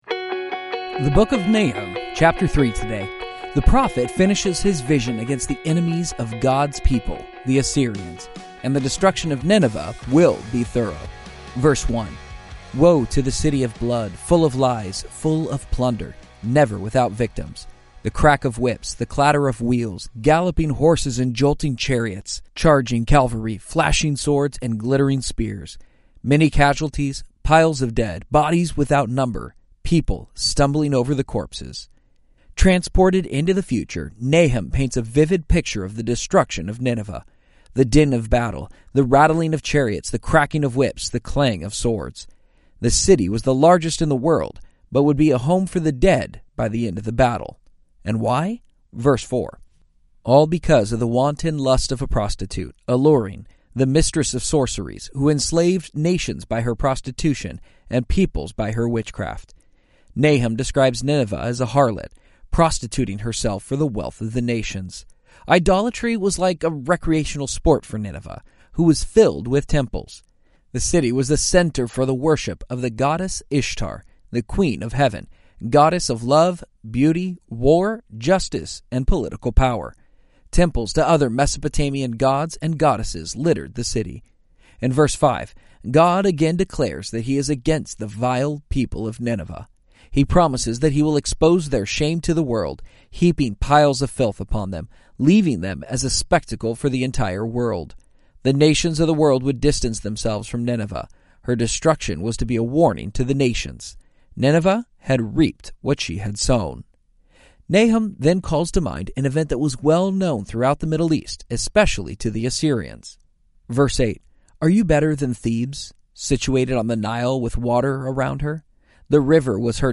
This devotional works best as an audio experience.